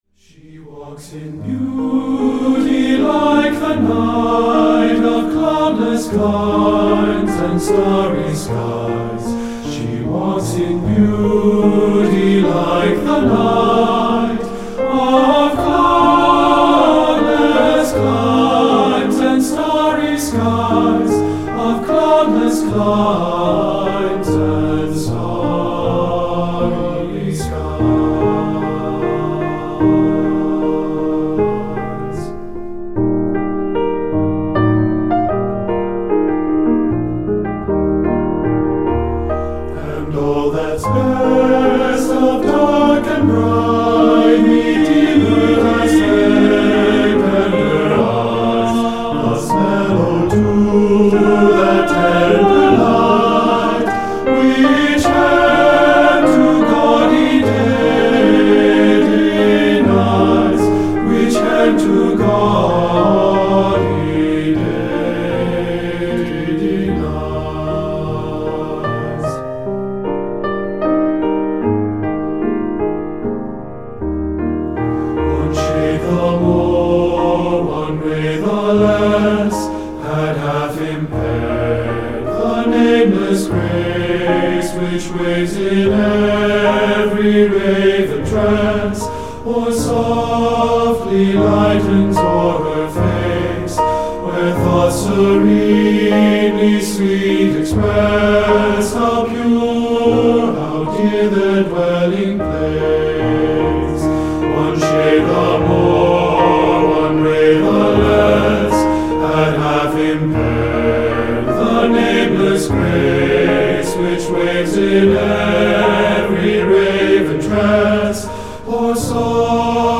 Choral Male Chorus
TTBB